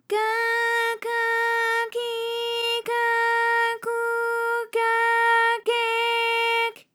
ALYS-DB-001-JPN - First Japanese UTAU vocal library of ALYS.
ka_ka_ki_ka_ku_ka_ke_k.wav